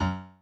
admin-fishpot/b_pianochord_v100l24o3fp.ogg at 595079ff8b5da54878cbcc810fdb542aacb3becd